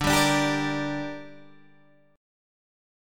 Dsus4#5 chord